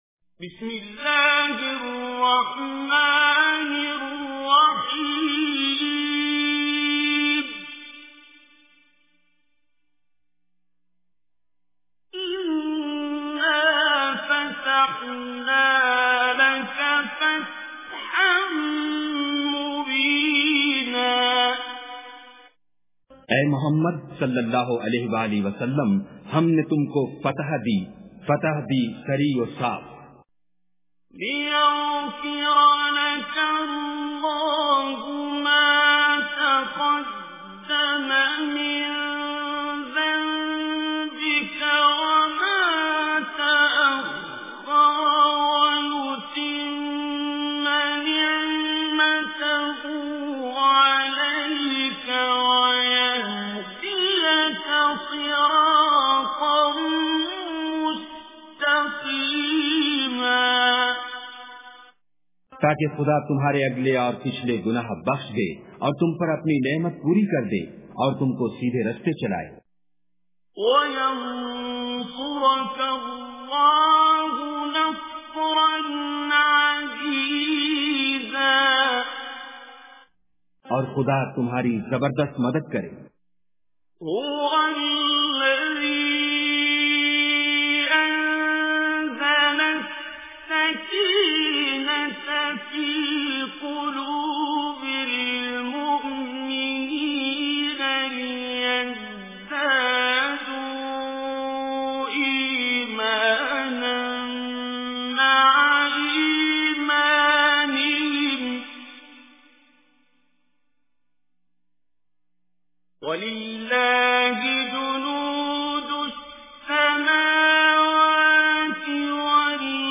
Surah Al Fath Recitation with Urdu Translation
Surah Fath is 48th chapter of Holy Quran. Listen online and download mp3 tilawat / recitation of Surah Al Fath in the beautiful voice of Qari Abdul Basit As Samad.